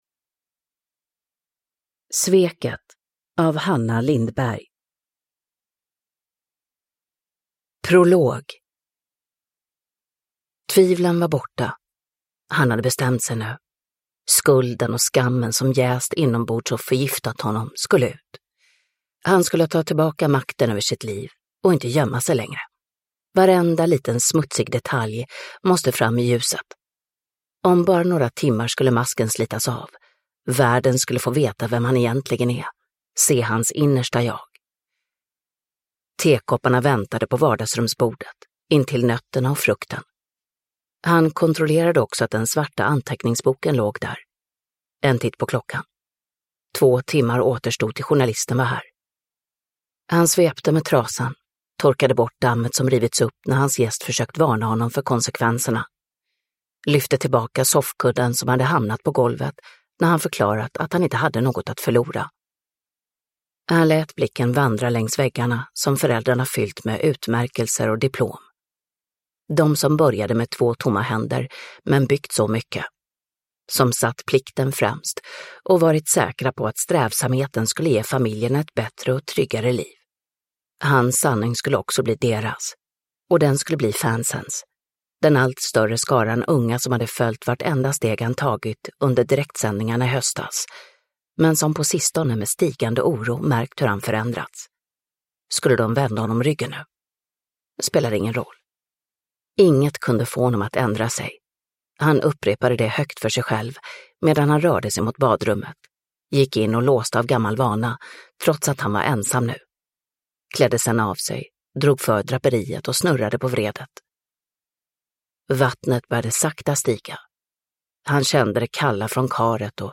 Sveket – Ljudbok
Uppläsare: Mirja Turestedt